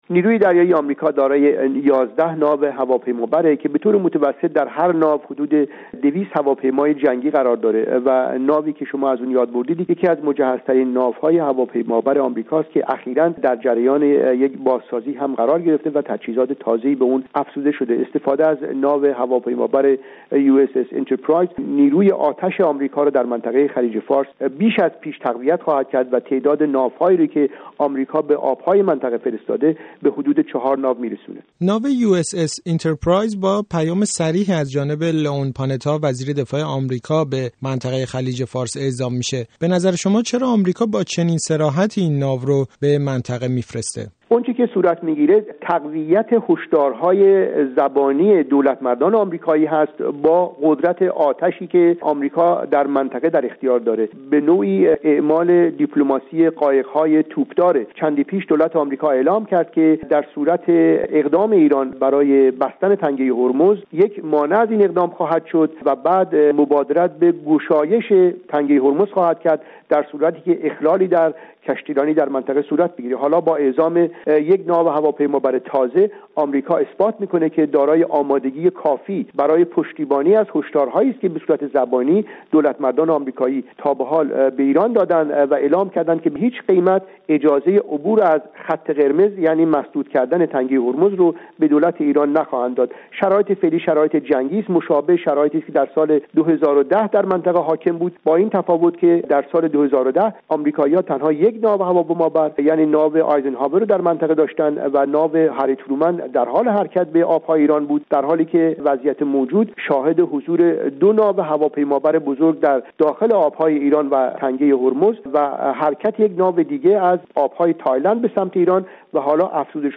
گفت و گوی